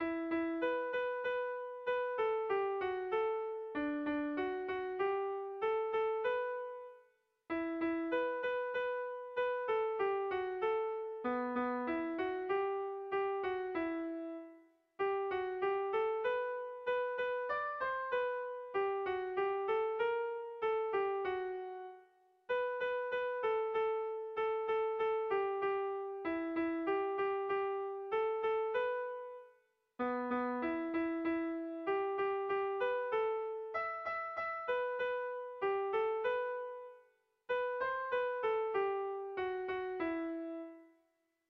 Kontakizunezkoa
Hamarreko handia (hg) / Bost puntuko handia (ip)
A1A2BD..